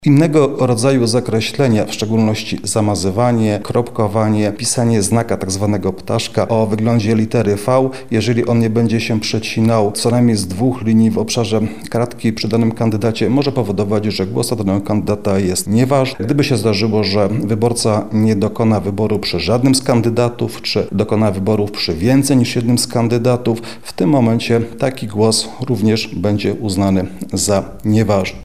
W kwadracie obok imienia i nazwiska kandydata należy wyznaczyć znak przecinających się linii popularnie nazywany Xem – mówi sędzia Krzysztof Niezgoda, przewodniczący Okręgowej Komisji Wyborczej w Lublinie.